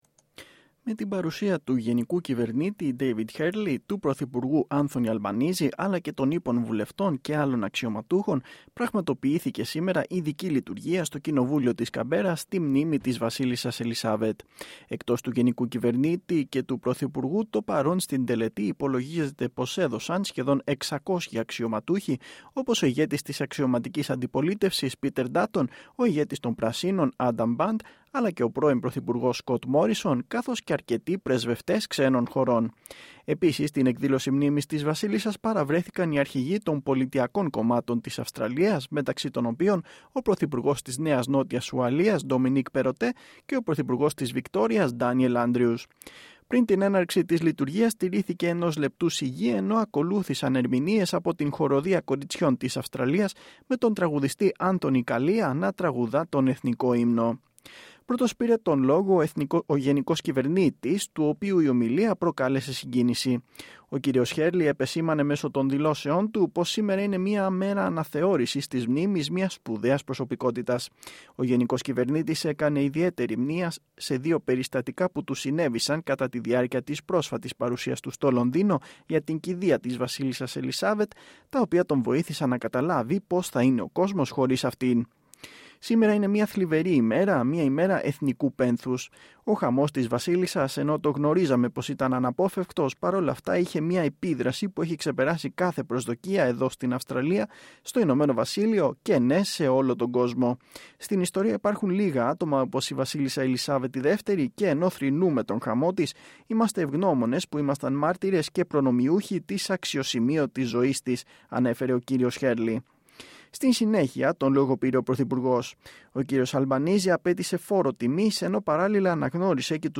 Ο πρωθυπουργός Anthoy Albanese κατά την διάρκεια ομιλίας του στο Κοινοβούλιο στην μνήμη της Βασίλισσας Ελισάβετ.